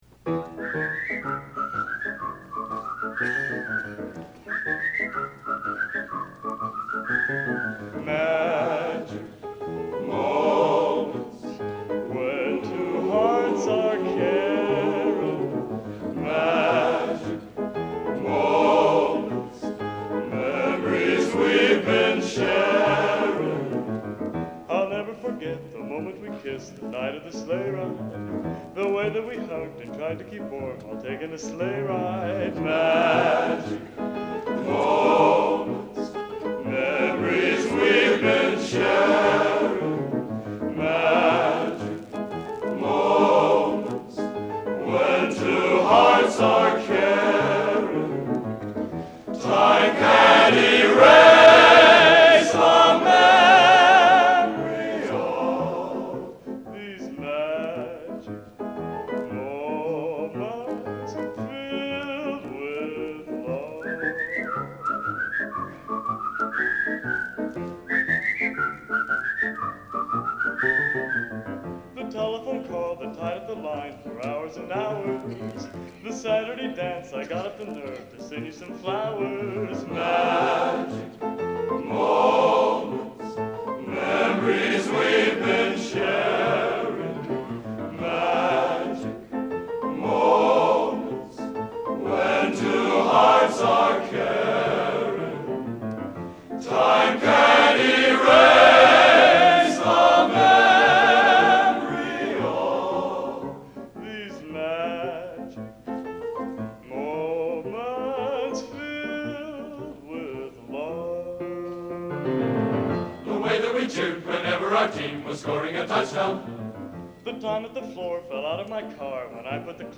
Location: Elliott Hall of Music, West Lafayette, IN
Genre: Schmalz | Type: Featuring Hall of Famer |Solo